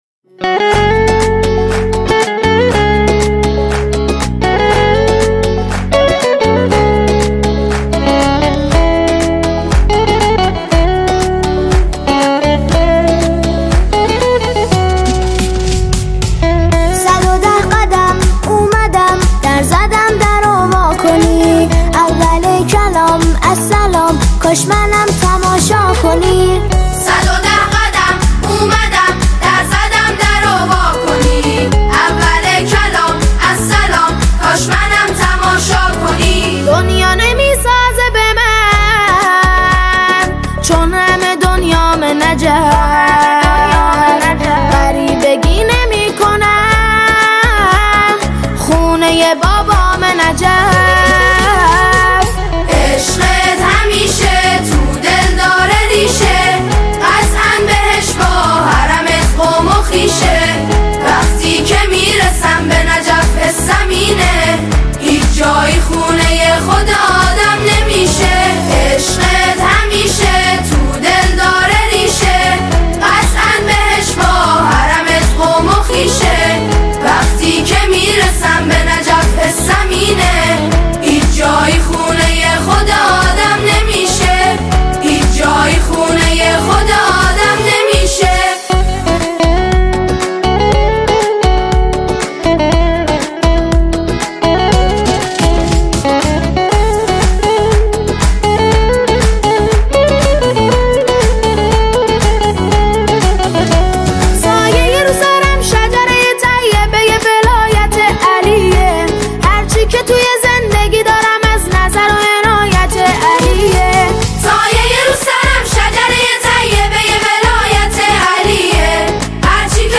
عید غدیر خم